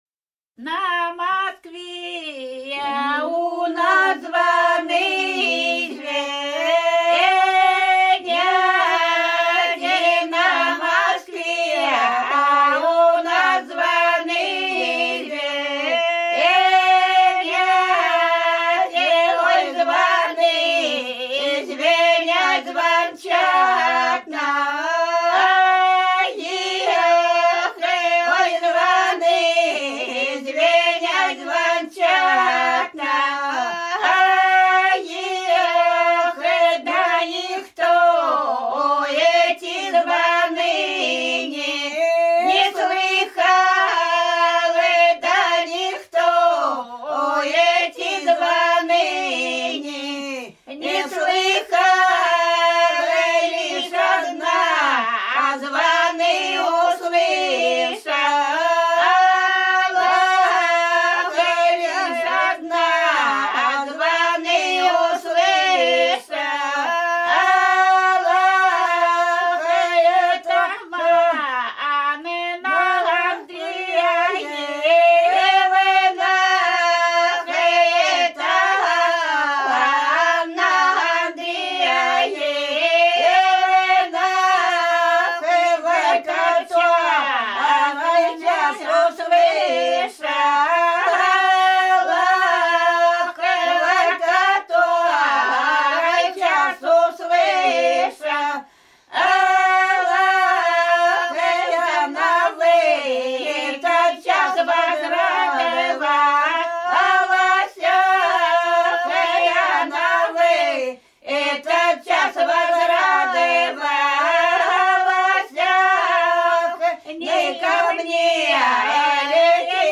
Рязань Кутуково «На Москве у нас звоны», свадебная.